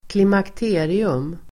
Ladda ner uttalet
Uttal: [klimakt'e:rium]